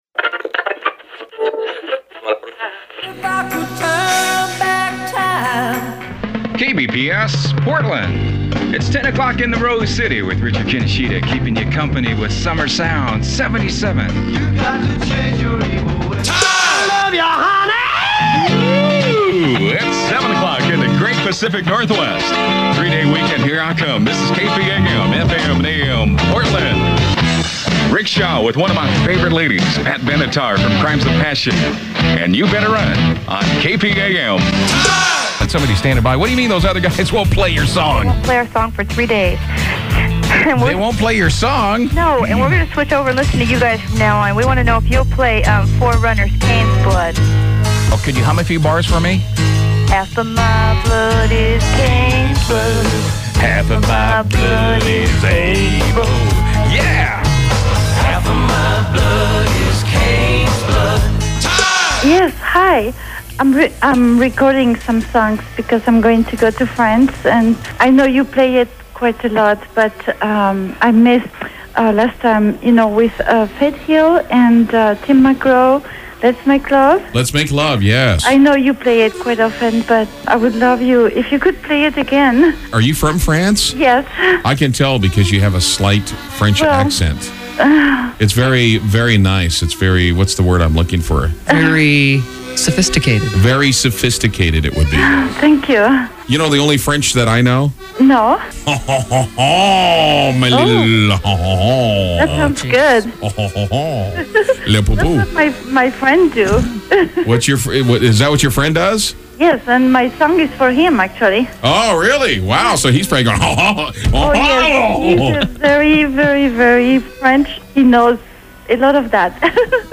Aircheck From Multiple Stations in Portland